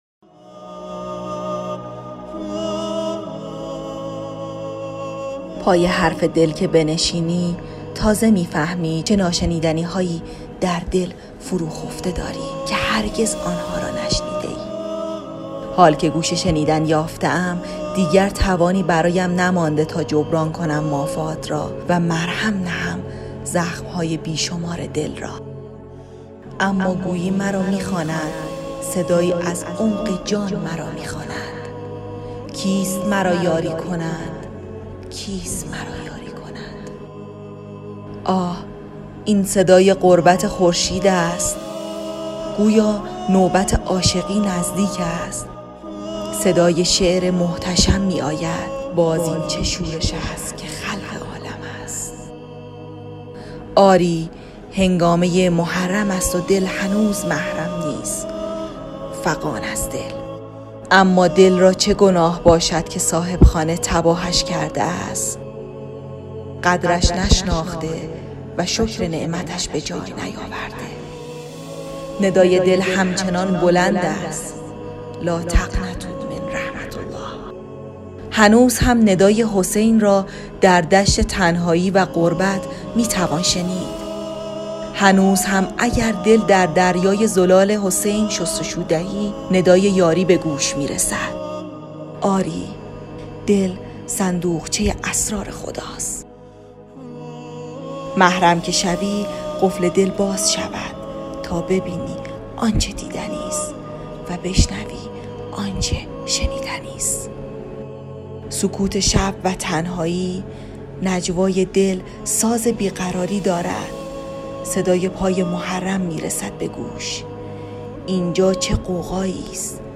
ایکنا در ایام سوگواری حضرت اباعبدالله حسین(ع)، مجموعه پادکست ادبی «با کاروان شهادت» را با موضوع کاروان امام حسین‌(ع) تولید و نهمین قسمت این مجموعه را تقدیم نگاه مخاطبان گرامی می‌کند.